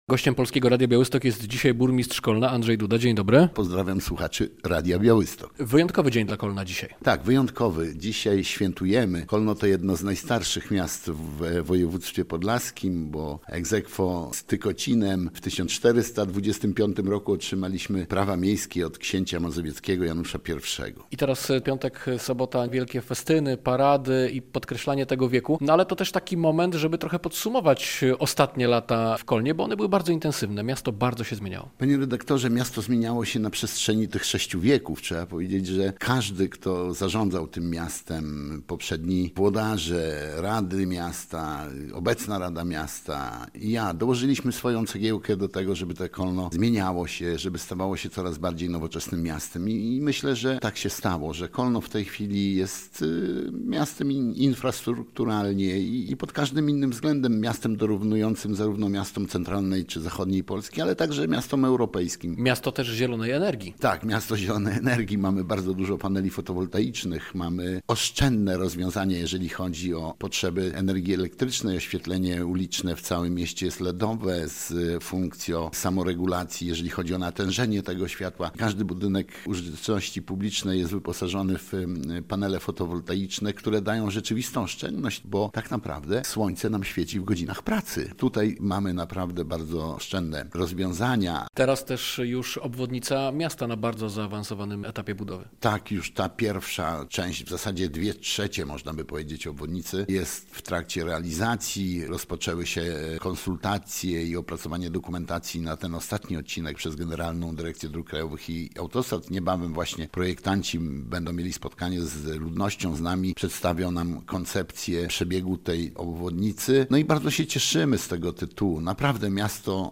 Radio Białystok | Gość | Andrzej Duda - burmistrz Kolna